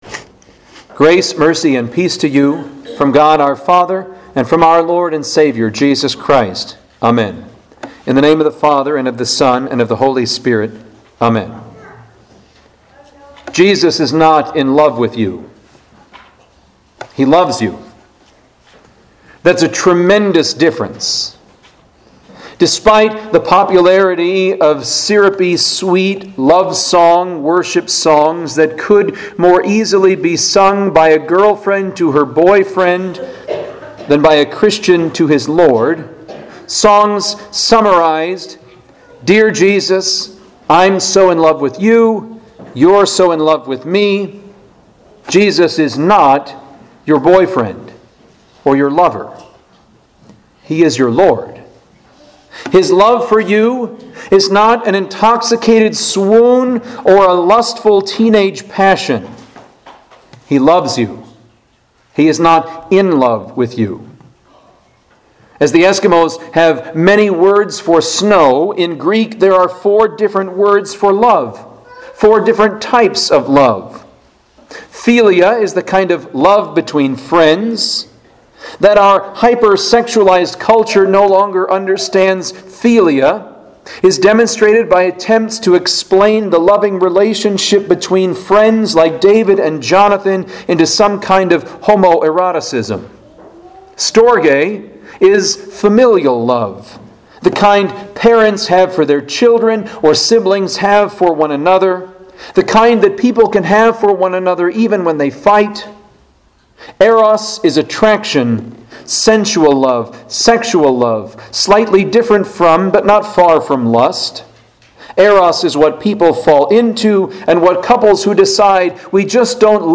Home › Sermons › Maundy Thursday